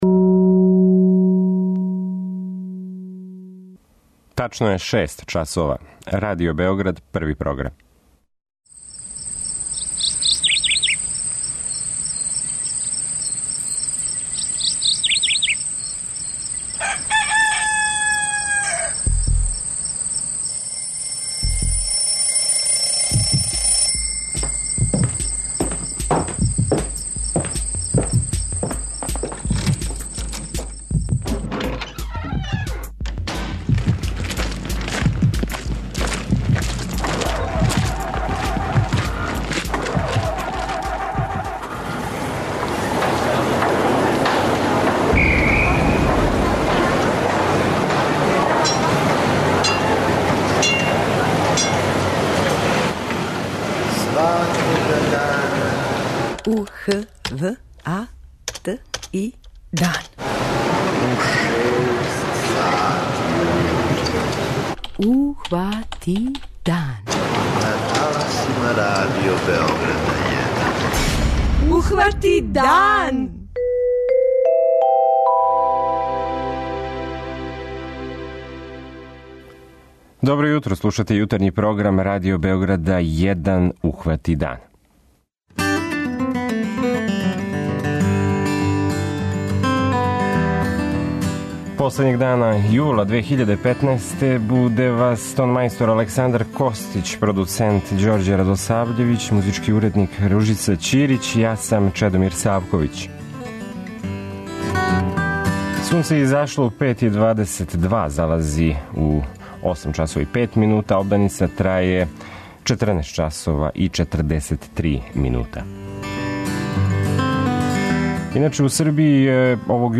преузми : 85.97 MB Ухвати дан Autor: Група аутора Јутарњи програм Радио Београда 1!